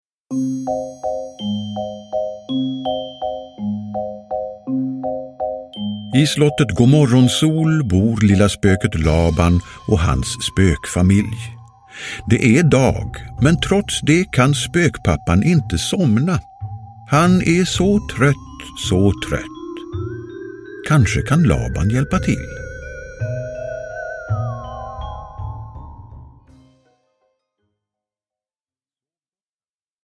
Sov gott pappa, sa lilla spöket Laban – Ljudbok – Laddas ner